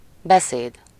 Ääntäminen
US : IPA : [ˈspiːt͡ʃ]